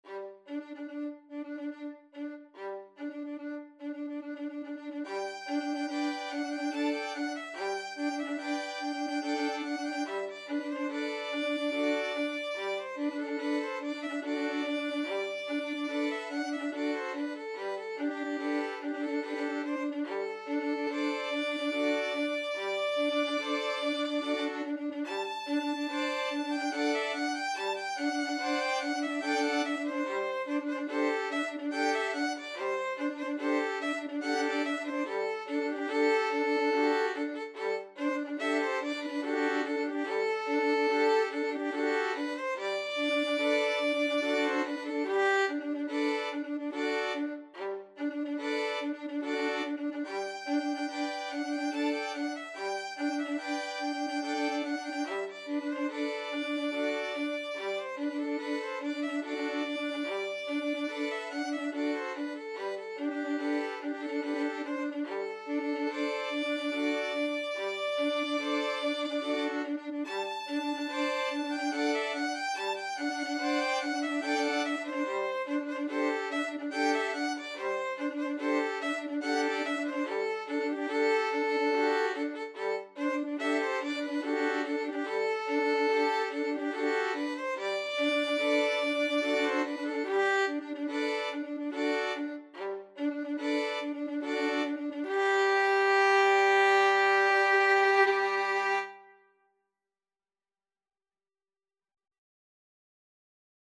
3/4 (View more 3/4 Music)
Classical (View more Classical Violin Quartet Music)